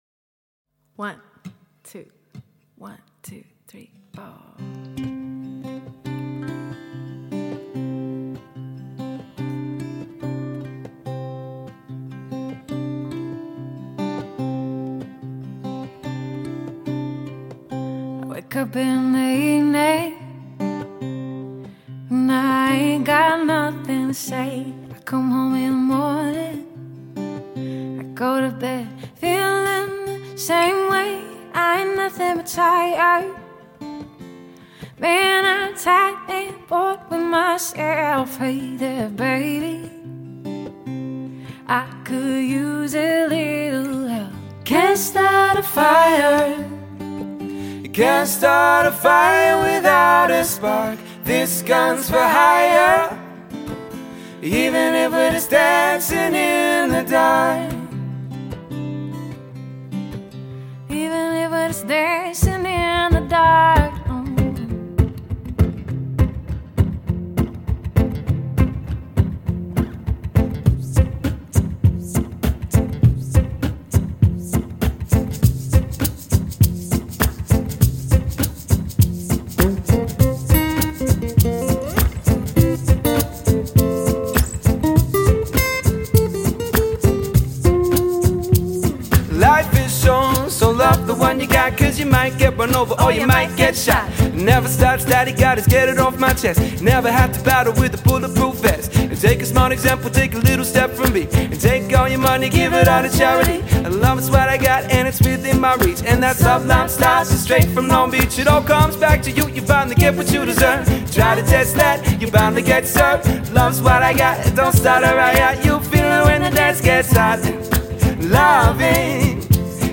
Male & Female Vocals | Guitars | Trumpet | DJ